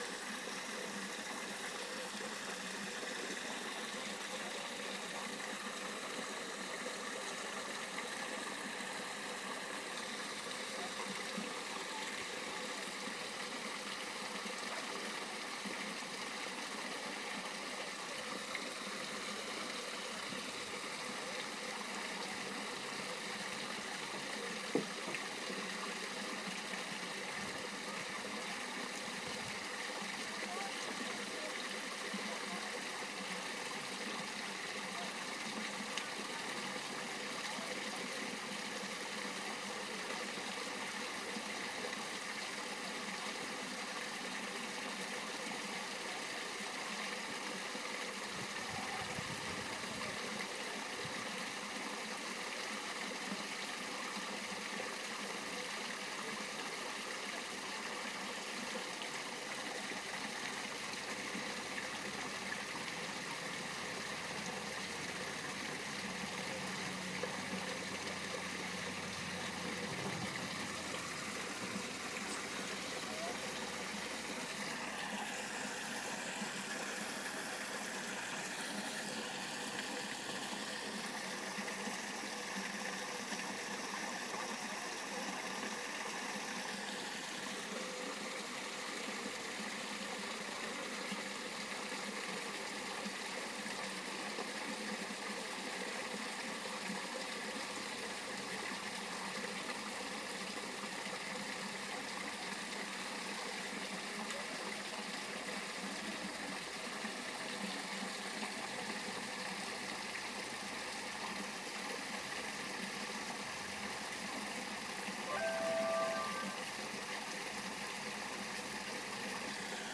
Waterfall Boo part 1. Mainly sound with chopped description at end